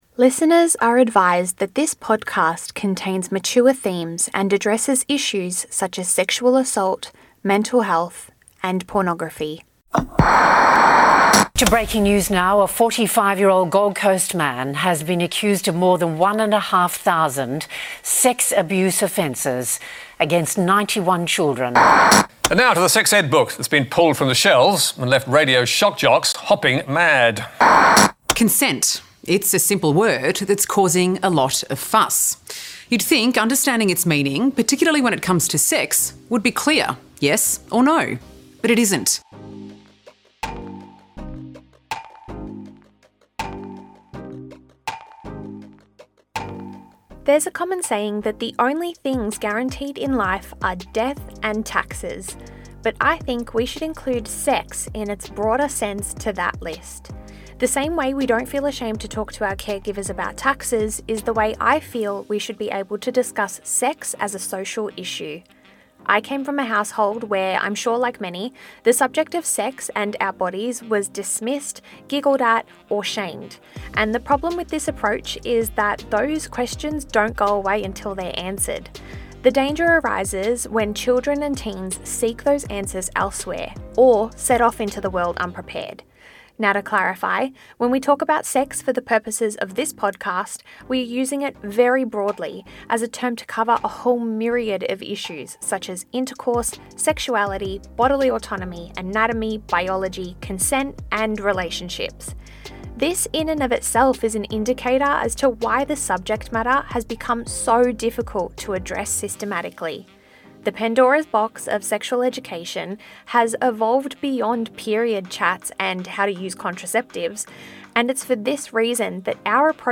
audio documentary